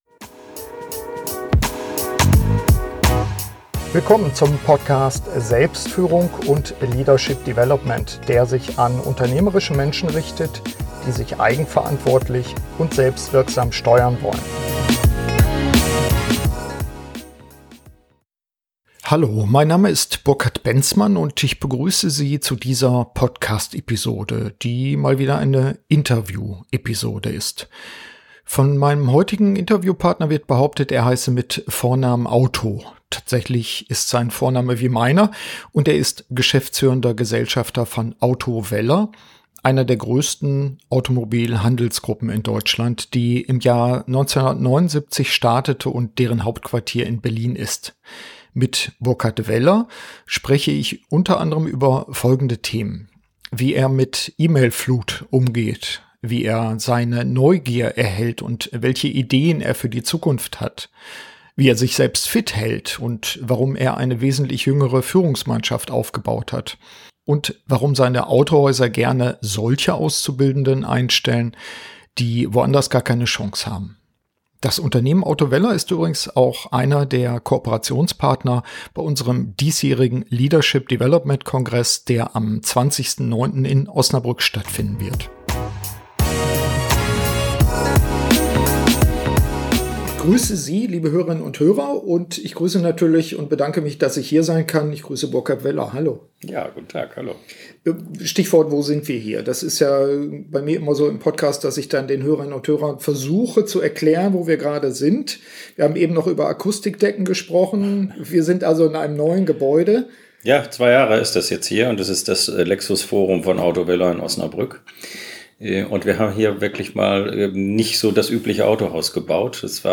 SF74 Wie wir unsere Neugier wach halten - Gespräch